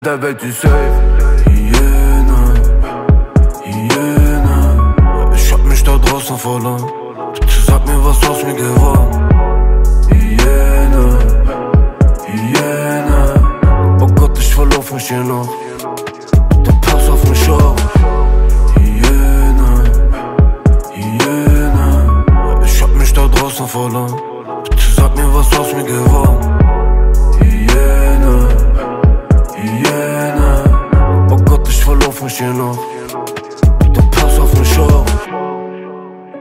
Kategorien Rap/Hip Hop